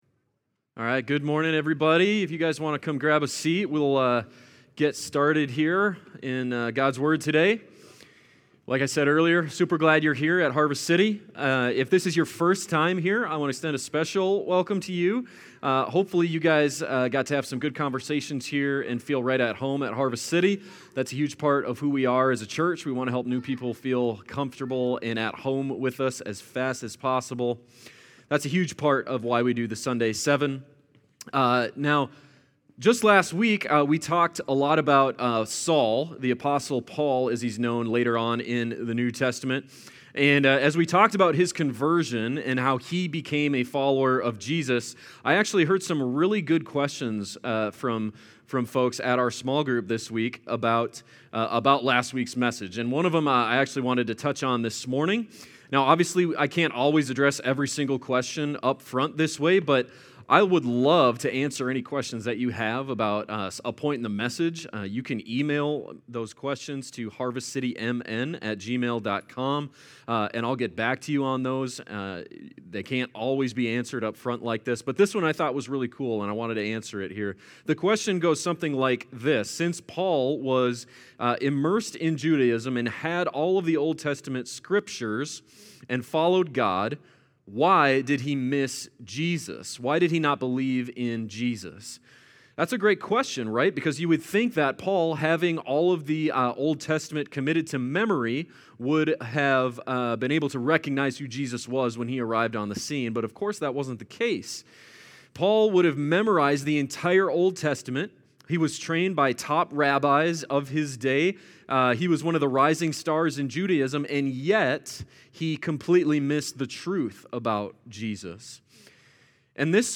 Sermon-Audio-12526.mp3